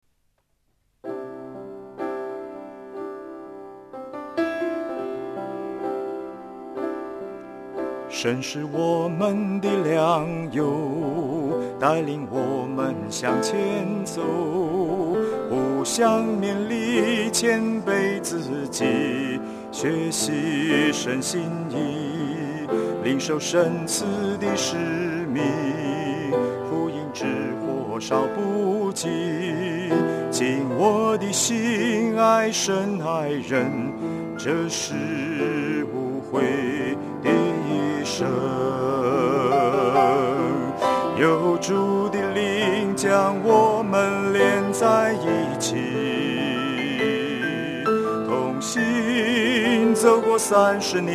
男声独唱 |
lts30_song_male.mp3